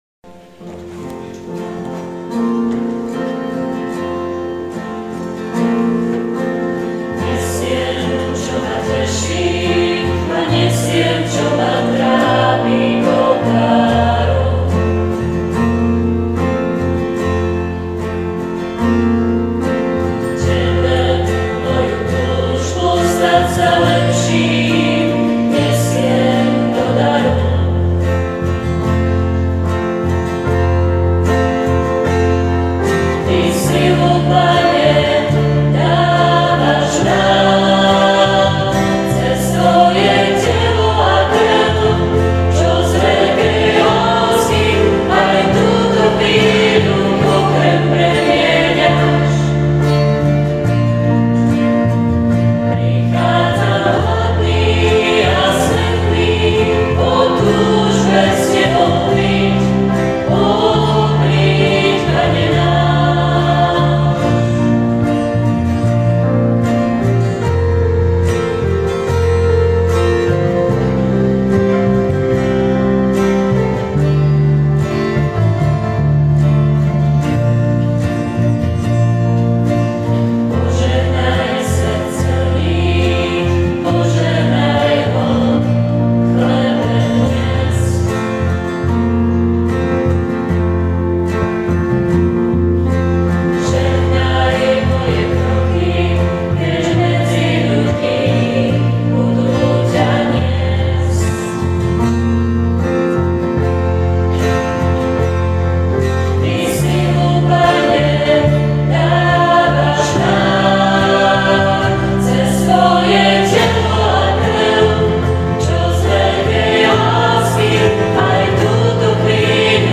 Zbor sv. Petra a Pavla v Seni
živé nahrávky piesní zo sv. omší :